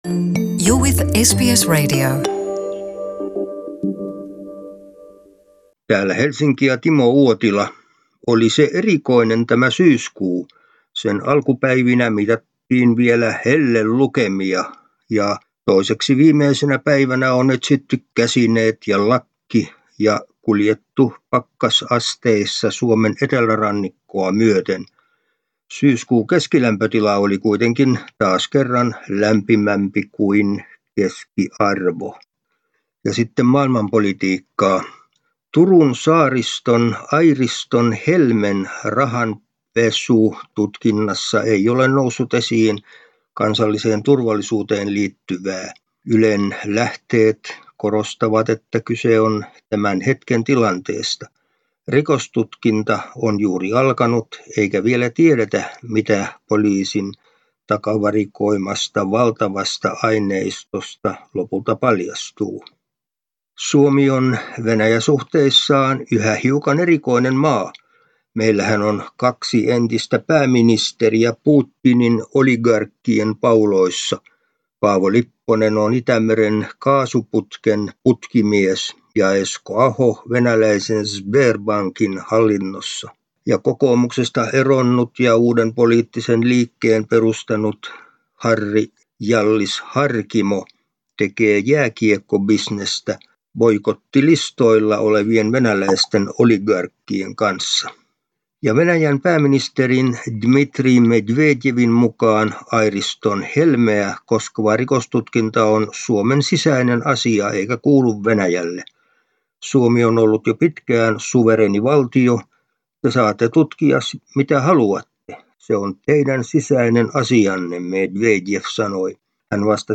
ajakohtaisraportti Suomesta